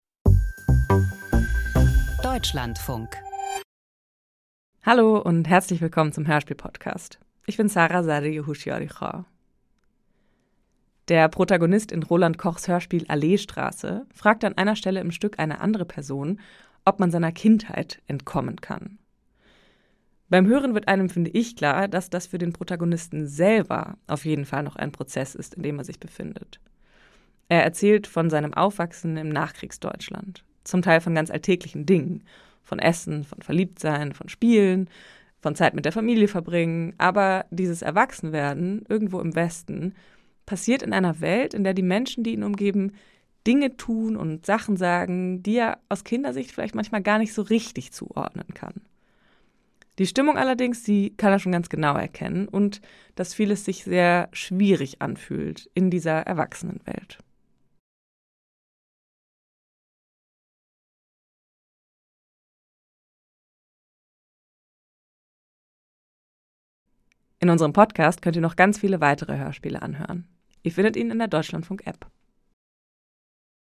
Hörspiel